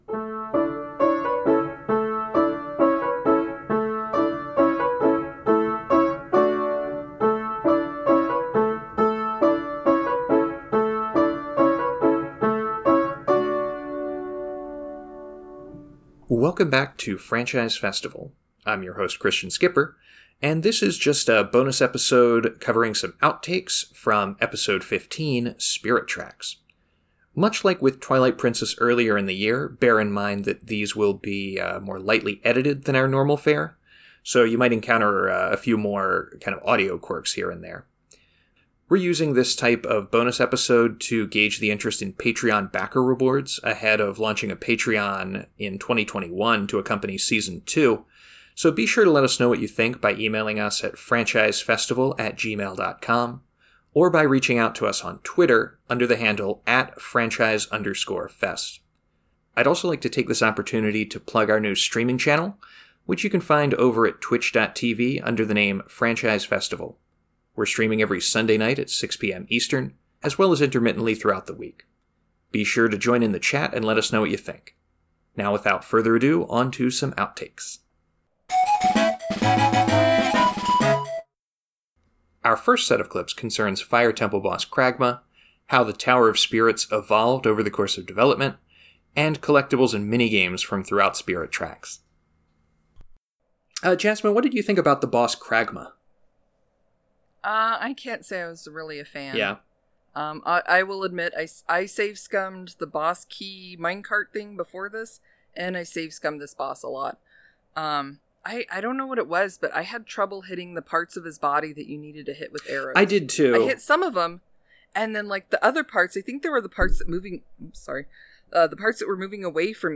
Note that audio quality and editing may be a touch rougher than standard episodes.